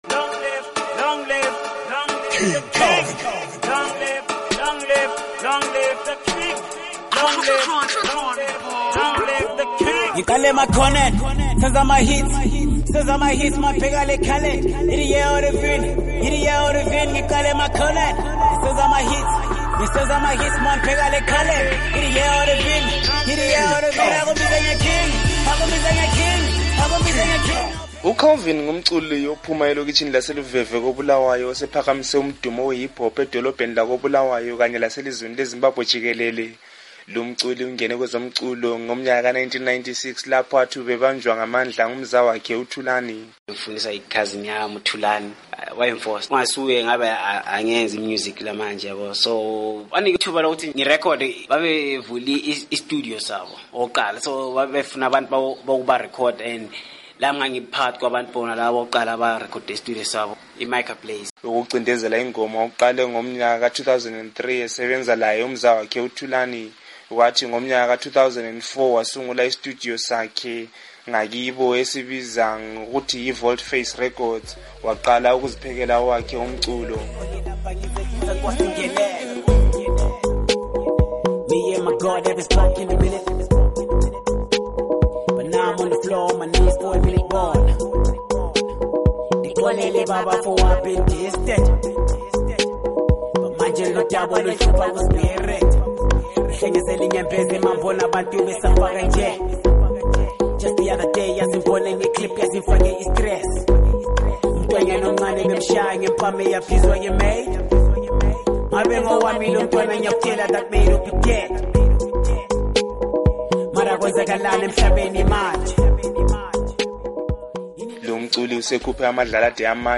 usethulela ingxoxo ayenze lomculi wengoma ze Hip Hop